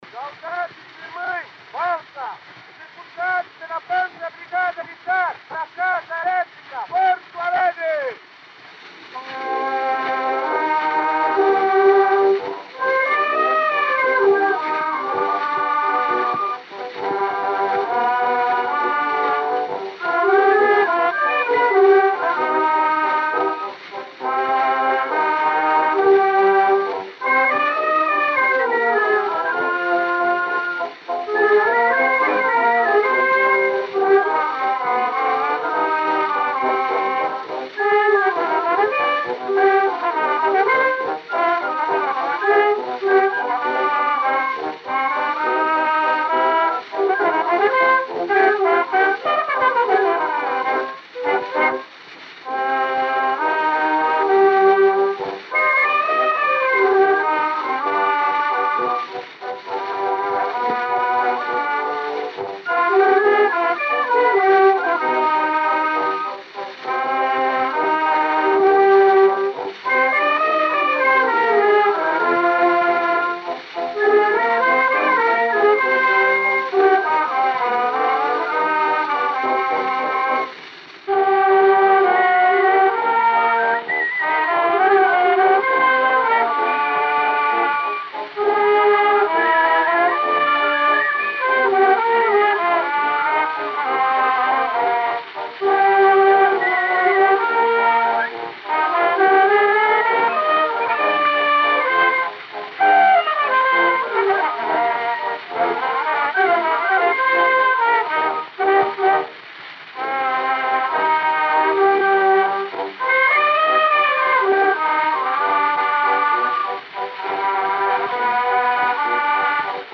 o gênero musical foi descrito como "Valsa" e